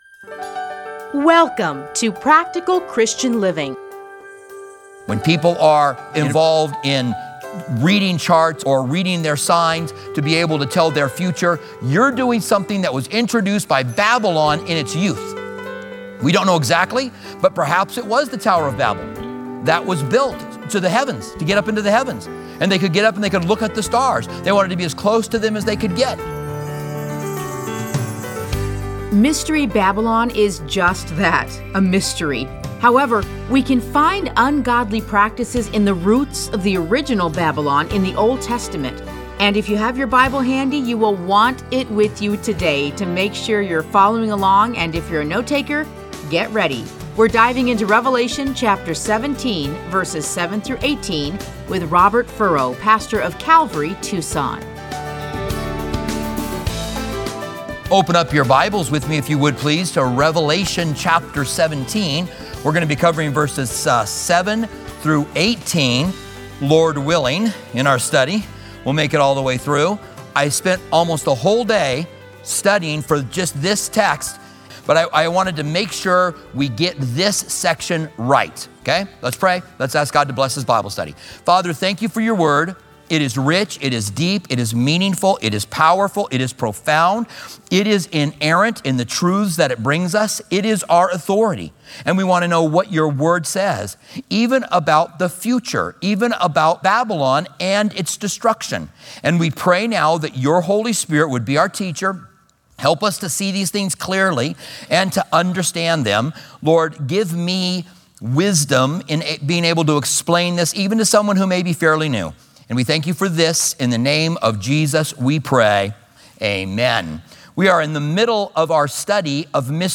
Listen to a teaching from Revelation 17:7-18.